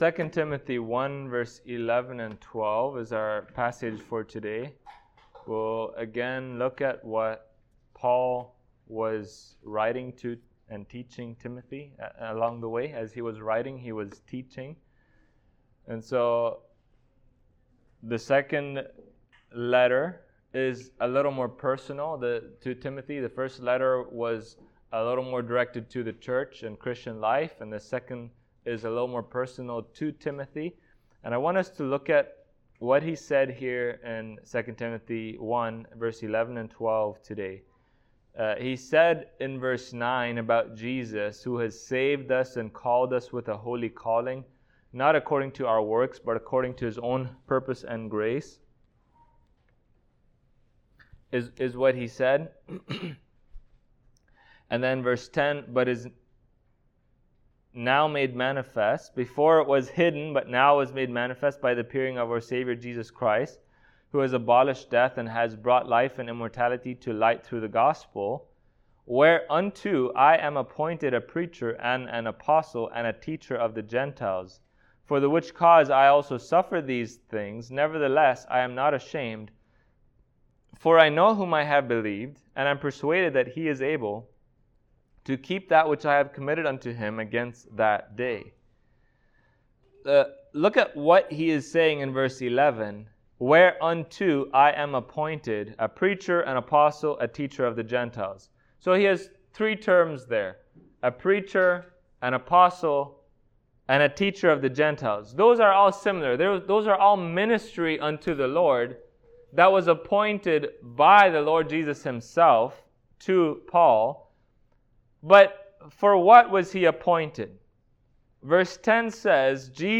2 Timothy 1:11-12 Service Type: Sunday Morning God appointed Paul a preacher of the Gospel.